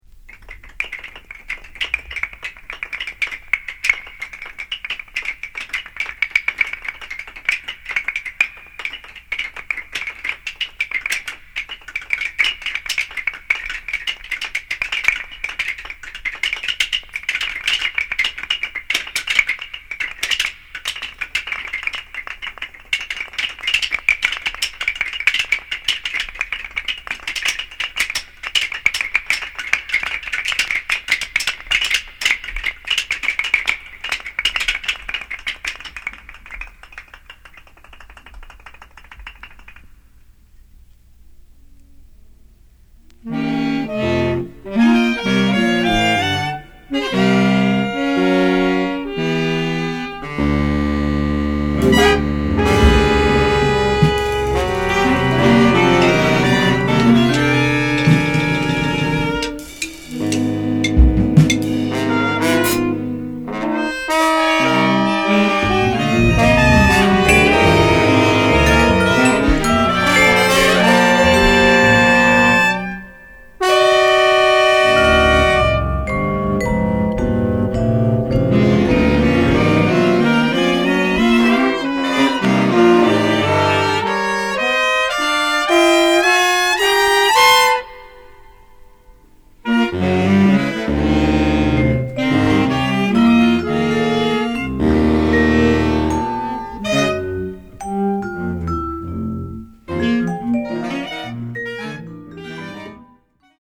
分厚い音の壁と沈黙とが迷宮状に配置された極めて構築的な仕上がり。